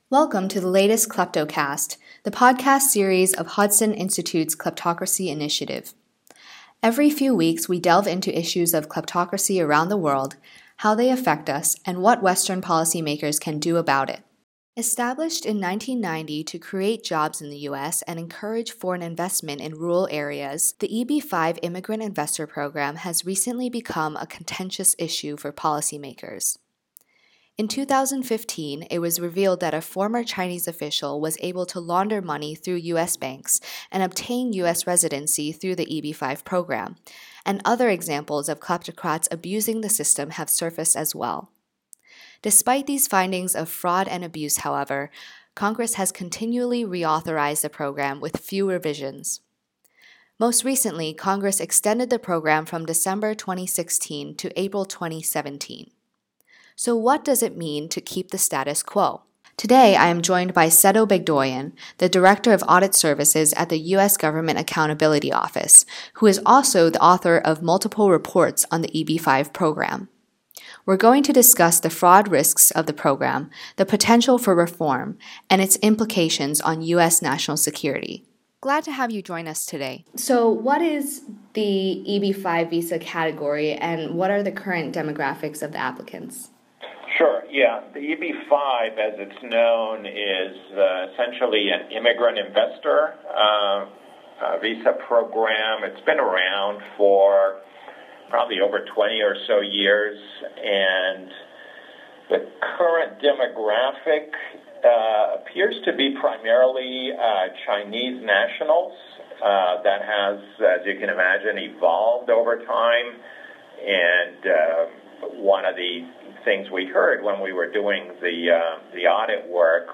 EB-5-Interview-Final.m4a